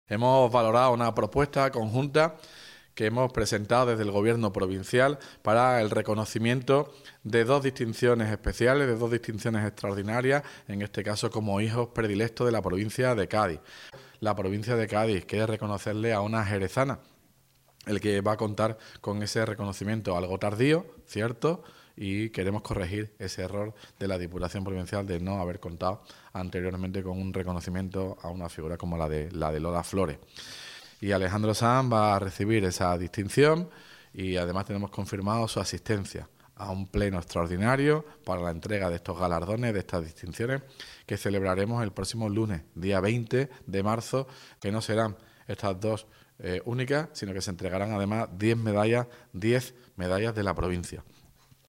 Ruiz-Boix-valora-las-distinciones-del-Dia-de-la-Provincia.mp3